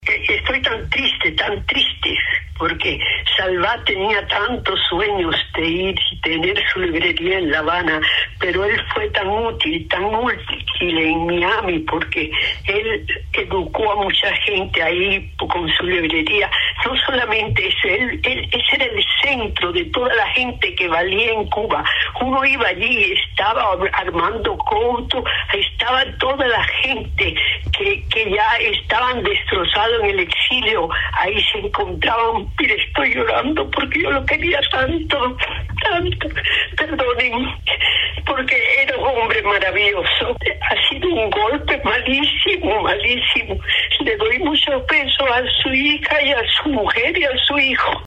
"Lo quería tanto, era un hombre maravilloso", dijo entre lágrimas sobre el fallecimiento del reconocido editor cubano Juan Manuel Salvat la viuda de Guillermo Cabrera Infante.